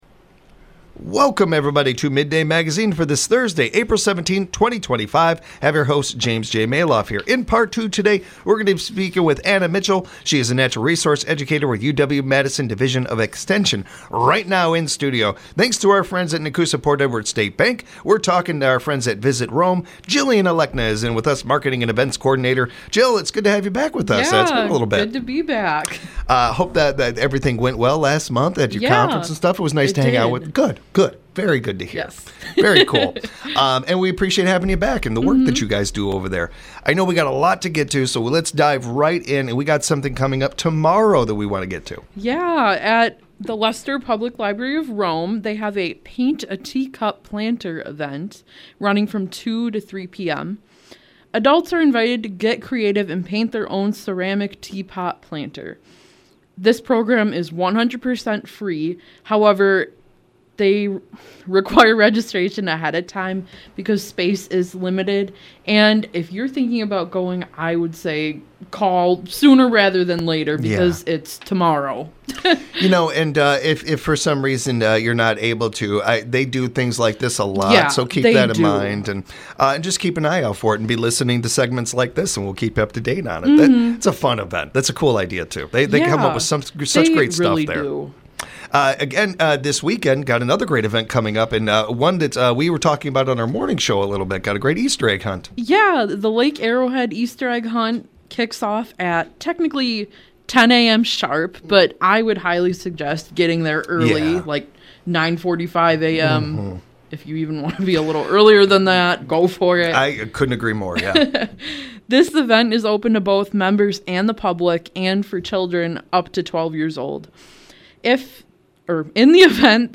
This interview is sponsored by Nekoosa Port Edwards State Bank.